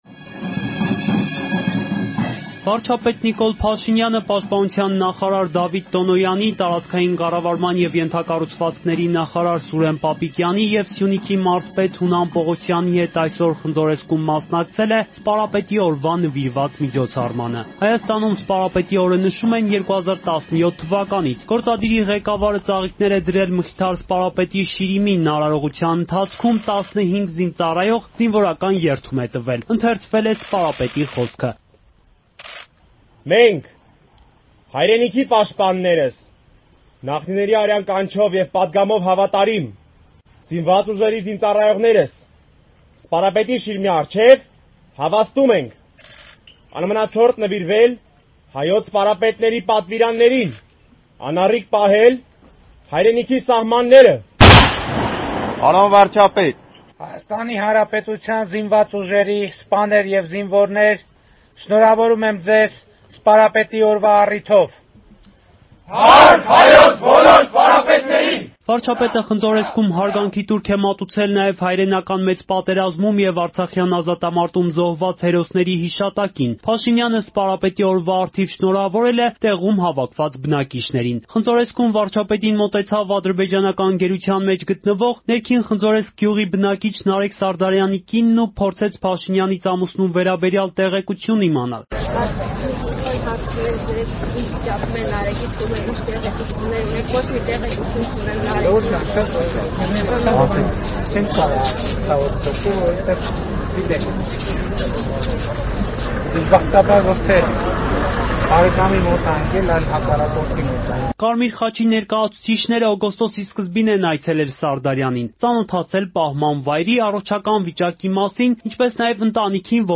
Ռեպորտաժներ
Վարչապետ Փաշինյանը Խնձորեսկում մասնակցել է Սպարապետի օրվան նվիրված միջոցառմանը